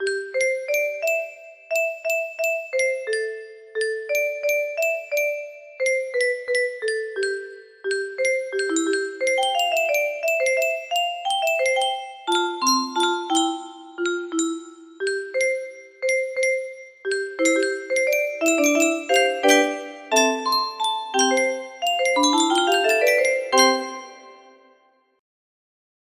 It seems like this melody can be played offline on a 15 note paper strip music box!